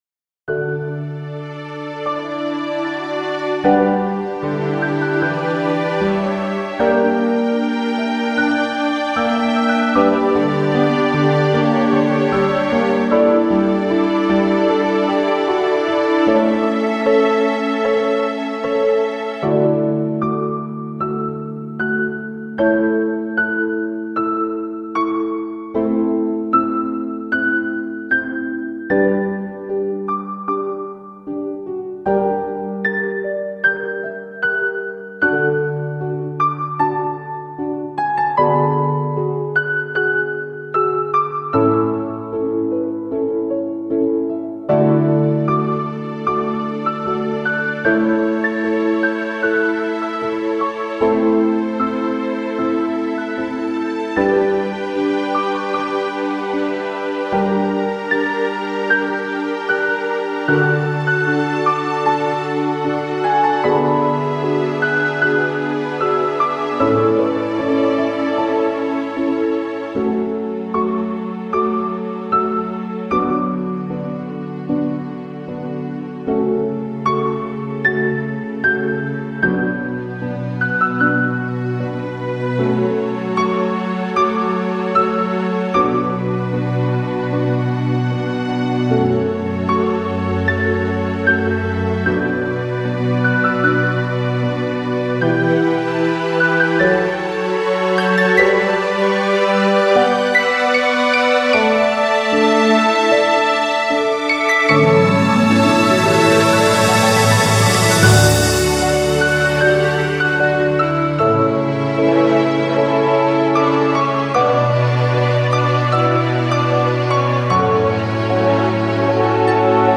エンドロール曲。
中盤　ＧＰテーマアレンジ
ちなみに、ピアノは主人公のイメージ、ストリングスはノエルのイメージ。
途中から入ってくる、ホルンがルイス、
ハープがフィリップ、ピッコロがデイジー、コンバスがリリー、チェロがエリック、
フルートが某重要キャラのイメージで編成を組みました。
いわゆる歌モノにはしません。
で長いから音質がえらく悪いのですが、そこはご了承のほどを。